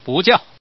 Index of /guizhou_ceshi_0/update/11363/res/sfx/ddz/man/
Man_NoOrder.mp3